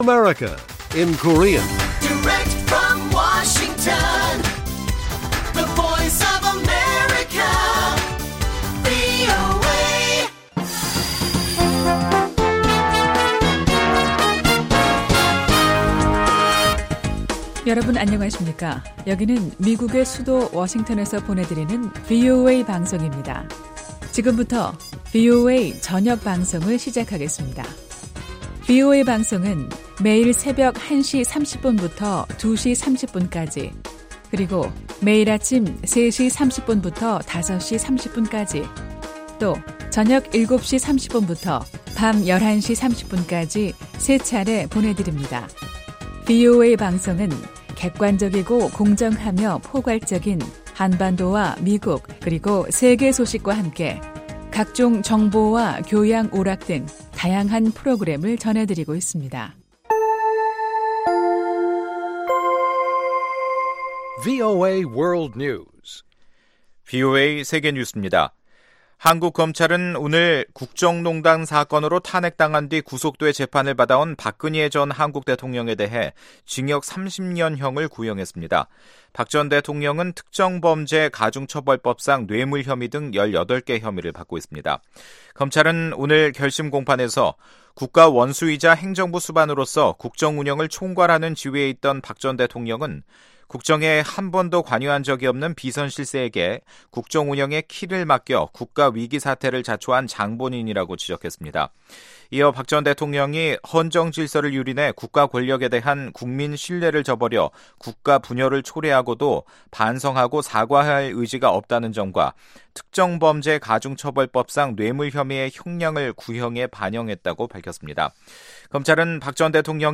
VOA 한국어 방송의 간판 뉴스 프로그램 '뉴스 투데이' 1부입니다.